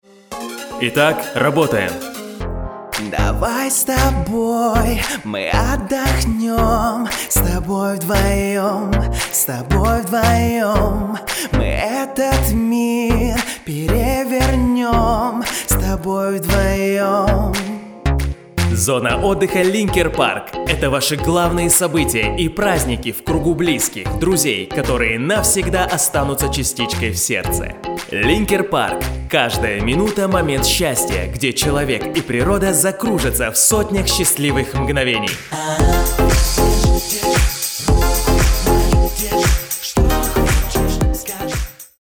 Муж, Вокал